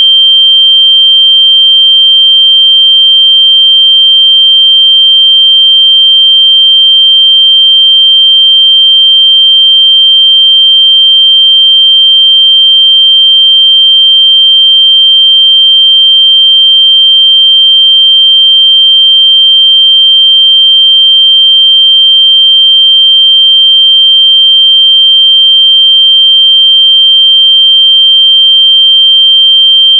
下面是用Multi-Instrument的信号发生器生成的30秒长的标准测试信号（WAV文件），可供下载。